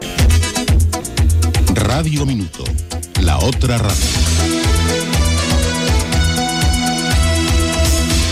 Indicatiu de la cadena Gènere radiofònic Musical